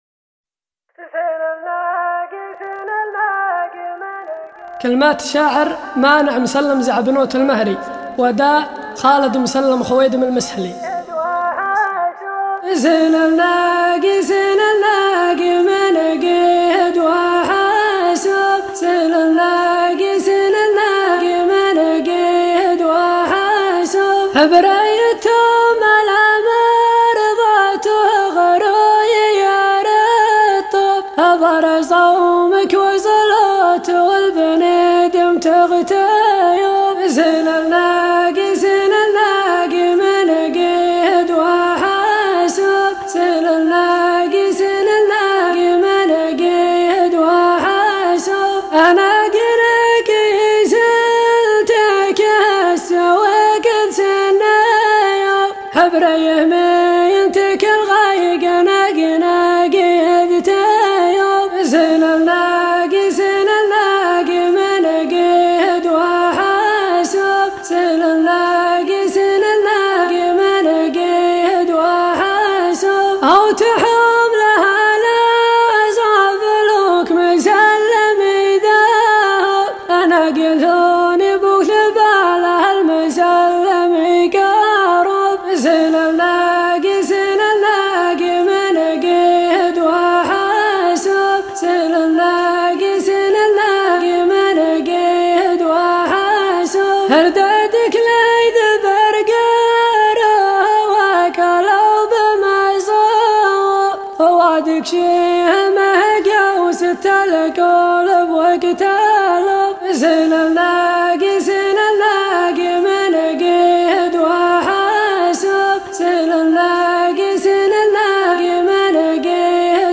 دبرارت ، ، نانا محليه ، صلاله ، ظفار ، فن الريفي ، تراث ، تراث ظفار ، فن النانا